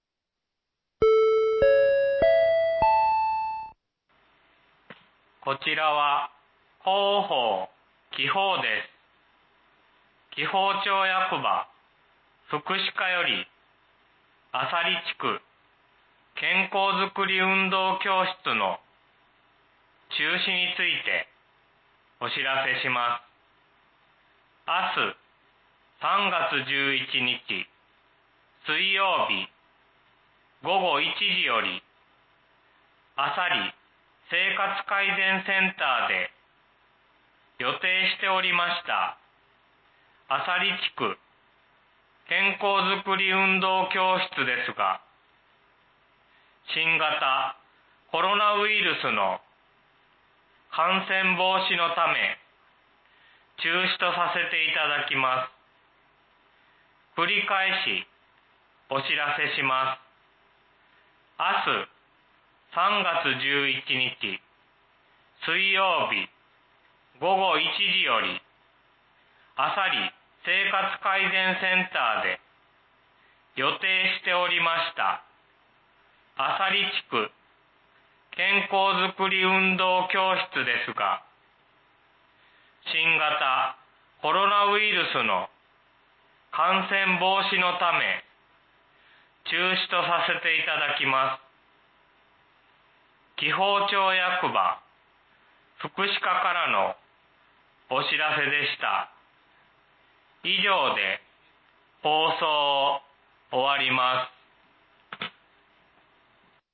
（浅里地区のみの放送です。）
放送音声